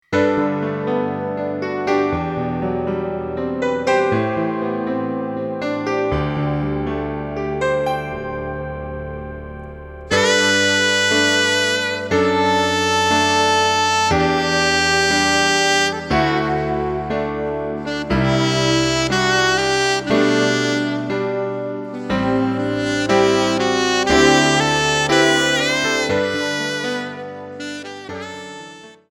Saxophone Alto ou Tenor et Piano